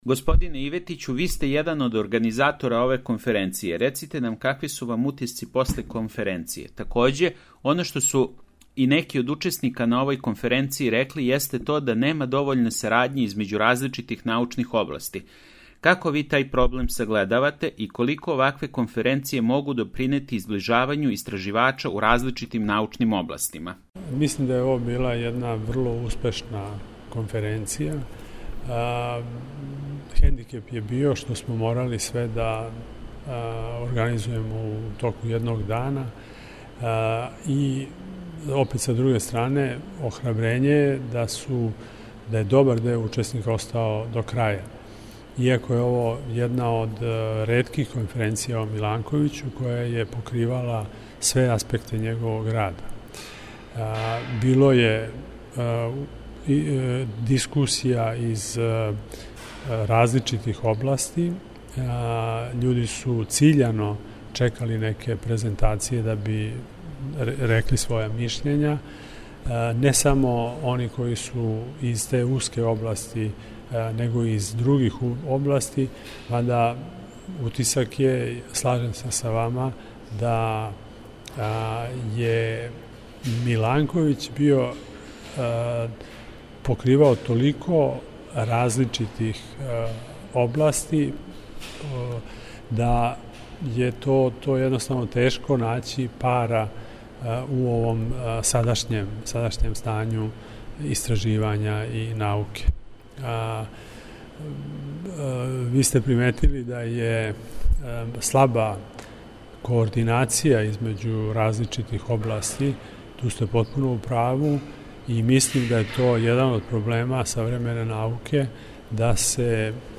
Изјава